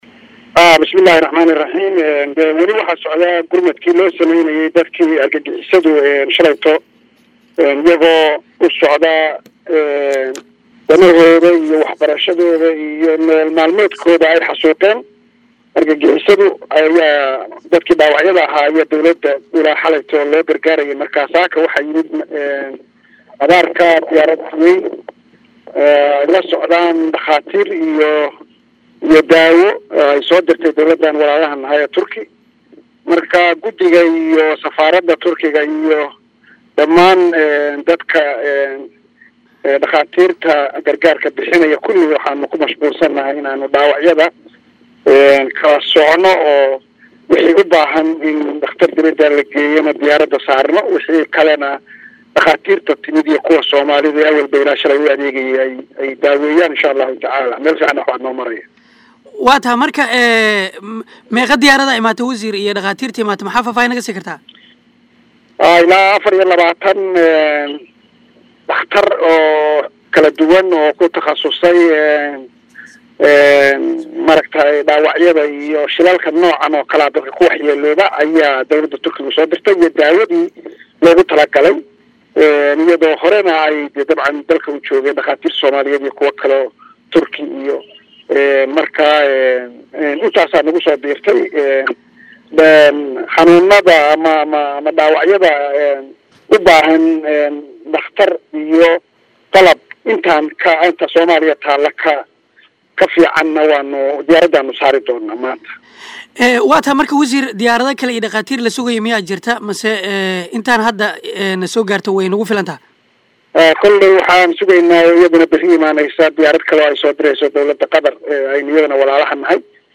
WAREYSI-WASIIRKA-WARFAAFINTA-.mp3